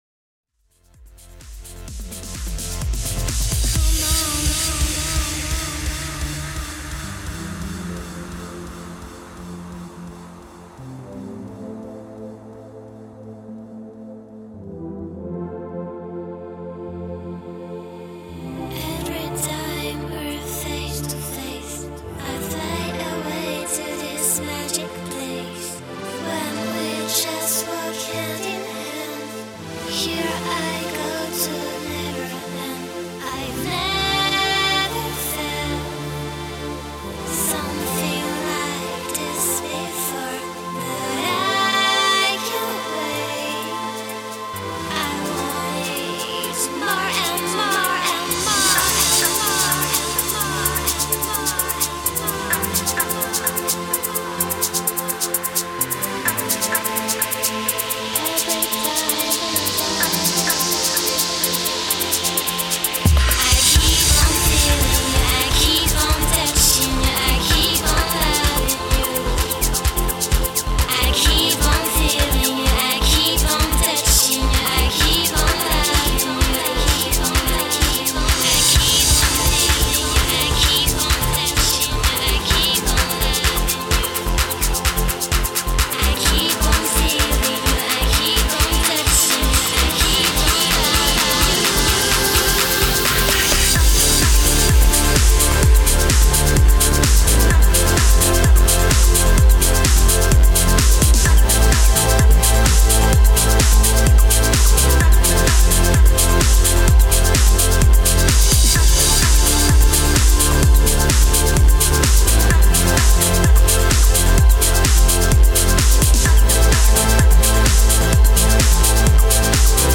J'aime beaucoup le vocal serieux!!!!
C'est bien punchy!
les synthés sont "beaux" surtout avec les side chain
Le kick ressort bien alors qu'il est pa trop puissant (faudrai m'apprendre comment faire sa :lol: )
Les hit hat son un peu trop en avant dans le break du début, le vocal c'est deja bcp mieux.